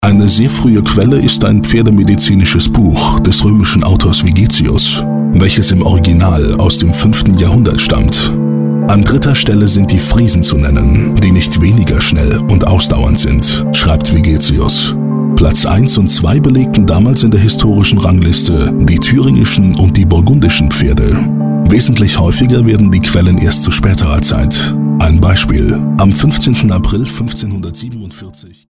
Sprechprobe: Industrie (Muttersprache):